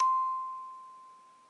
八音盒单音 " 05 d6
Tag: 俯仰-D6 单音符 音乐盒